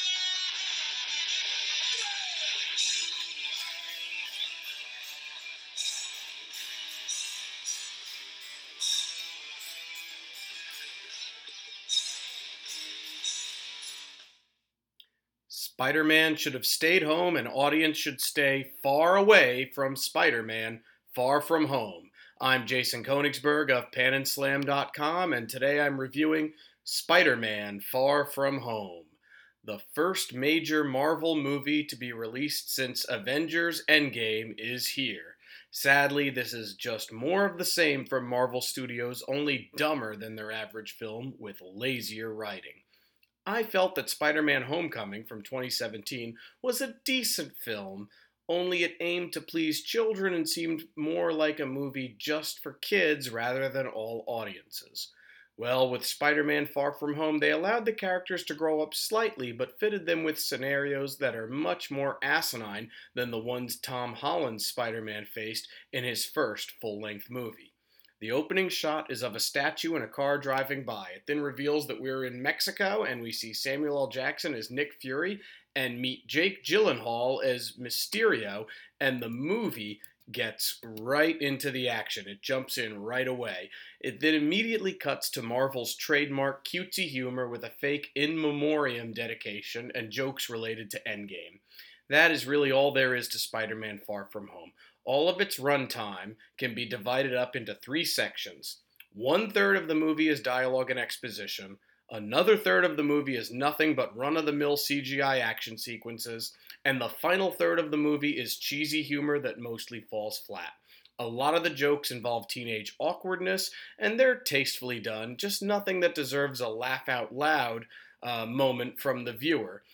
Movie Review: Spider-Man: Far From Home